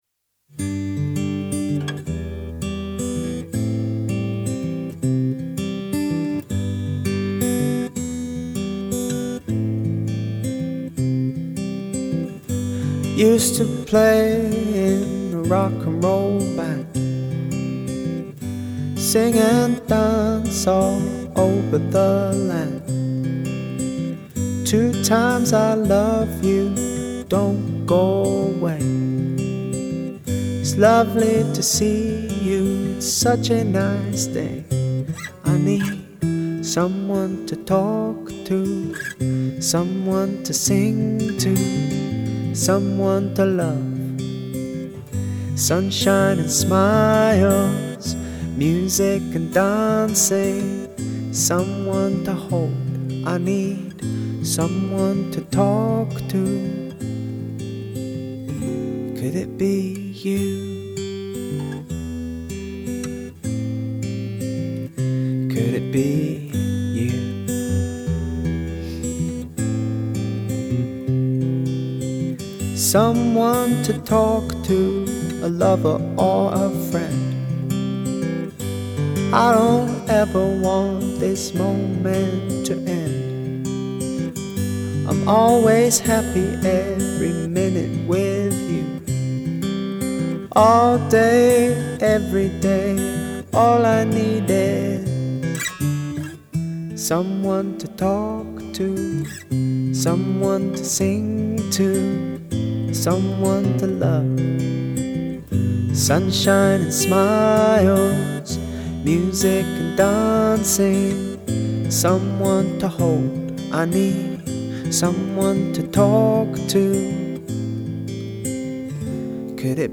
Once we’d written the song together, I took it into a music studio to record it.